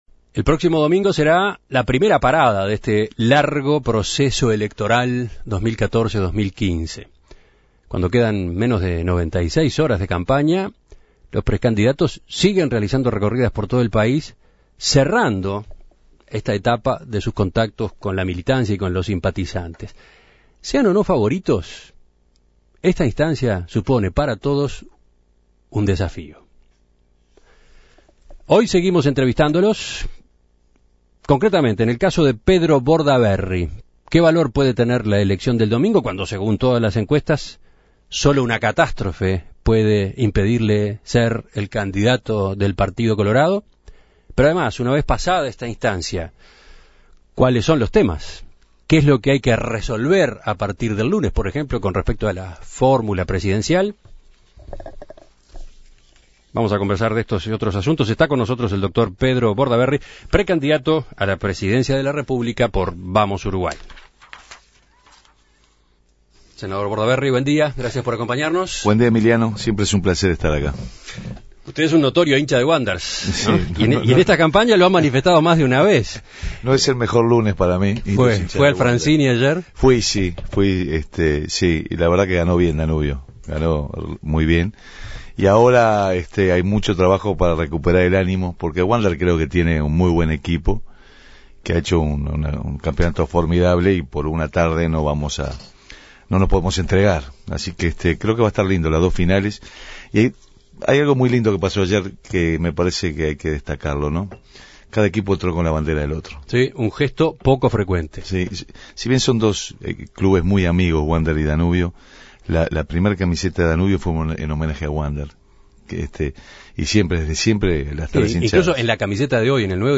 En la semana previa, En Perspectiva conversará con los precandidatos, y en esta oportunidad fue el turno de Bordaberry.